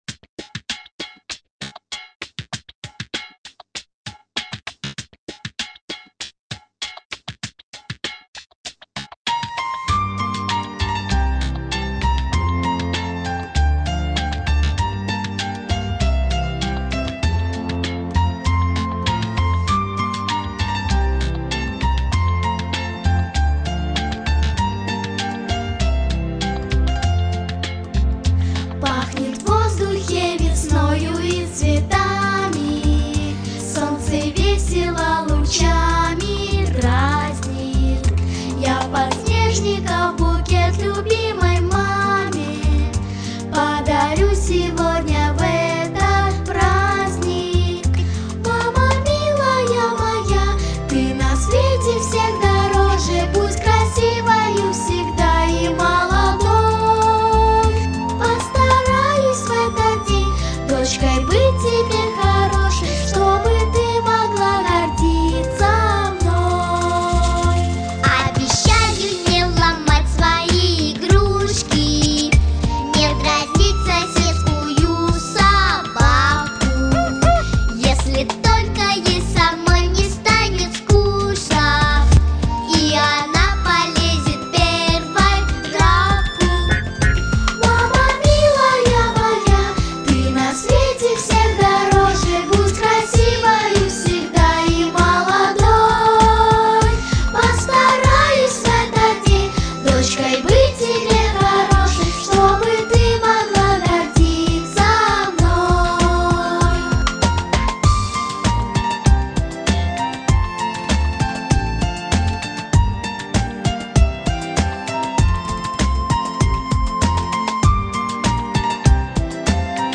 • Категория: Детские песни
детская песенка на день мамы